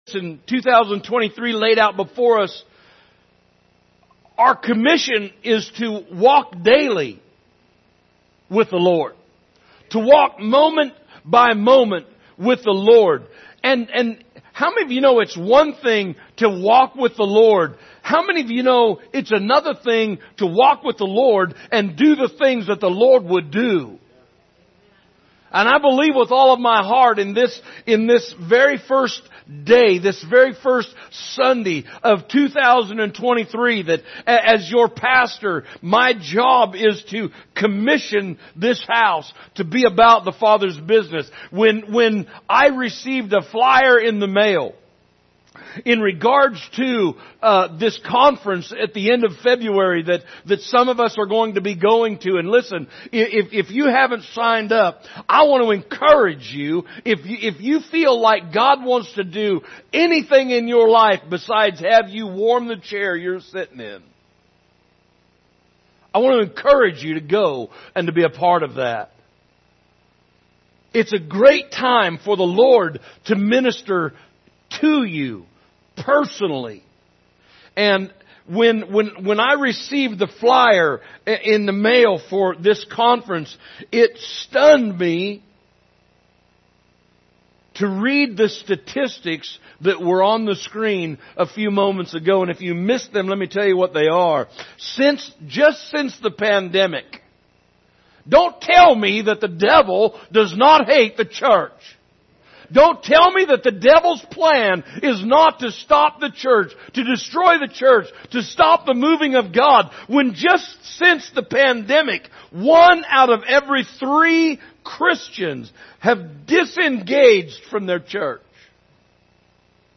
Sunday Morning Service January 15, 2023 – The Lord’s Supper
Category: Sermons